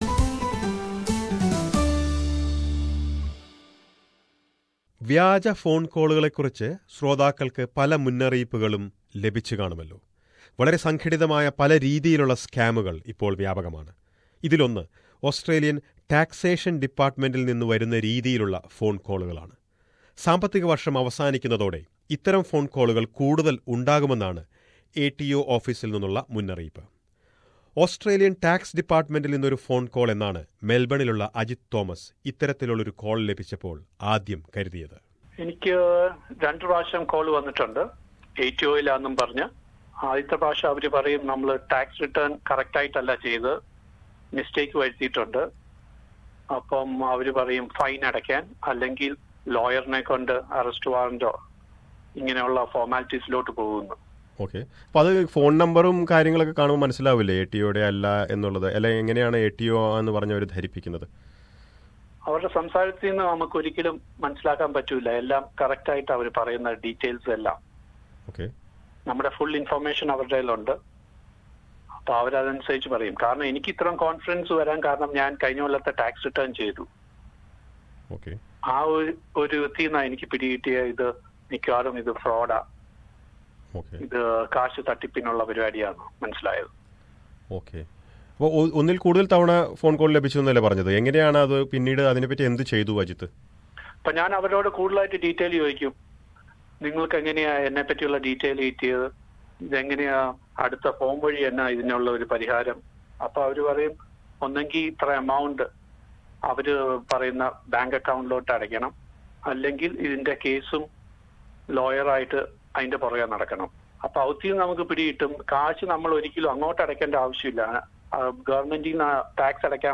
ഇത്തരത്തിൽ വ്യാജ ഫോൺകോളുകൾ ലഭിച്ചതിൽ നിരവധി മലയാളികളുമുണ്ട്. അവരുടെ അനുഭവങ്ങളും, നികുതി വകുപ്പിൻറെ മുന്നറിയിപ്പും കേൾക്കാം, ഈ റിപ്പോർട്ടിൽ...